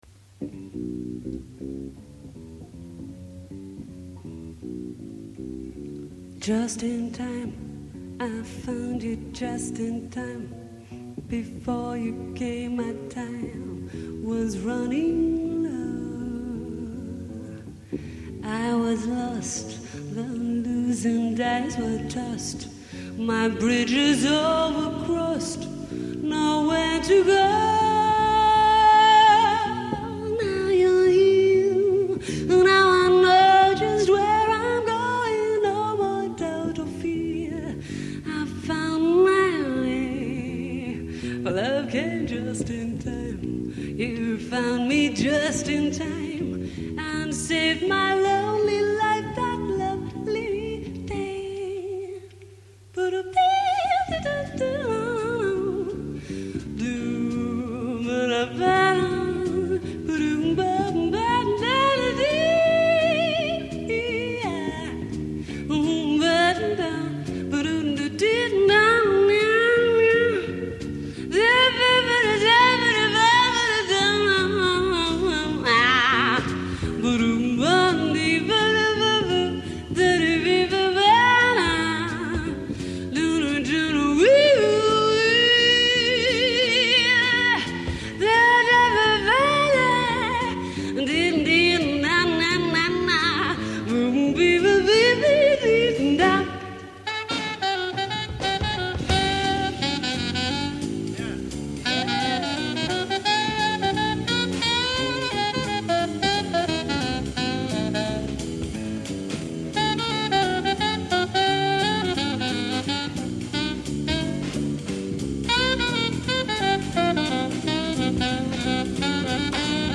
Vocal songs with Big Band Arrangements
5 Saxes, 4 Trumpets, 4 Trombones, Piano, Guitar, Bass, Drums
(Standard)